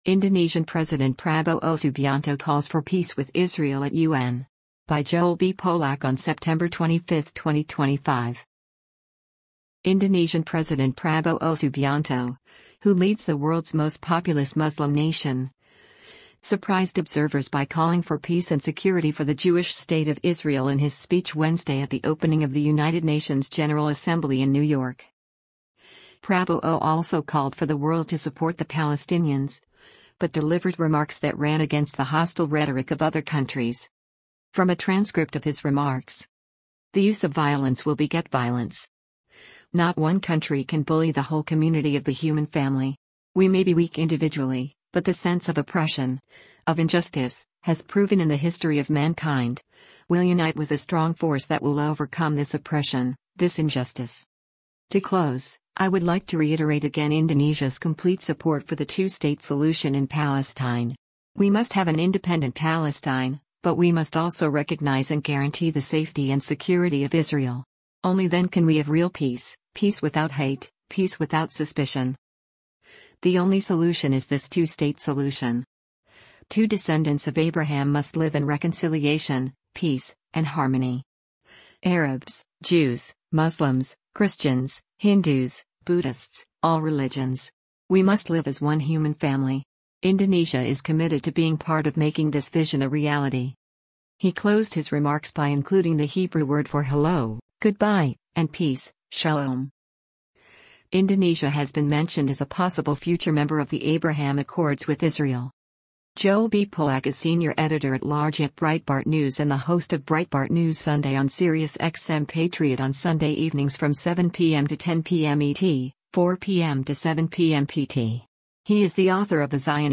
Indonesian President Prabowo Subianto, who leads the world’s most populous Muslim nation, surprised observers by calling for peace and security for the Jewish state of Israel in his speech Wednesday at the opening of the United Nations General Assembly in New York.
He closed his remarks by including the Hebrew word for hello, goodbye, and peace: “Shalom.”